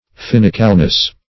Fin"i*cal*ness, n.